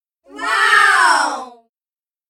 ambiance ambience applause audience background chants cheer cheering sound effect free sound royalty free Sound Effects